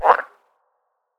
PSILOTOAD_idle1.ogg